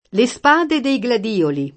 gladiolo [ g lad & olo ; raro g lad L0 lo ]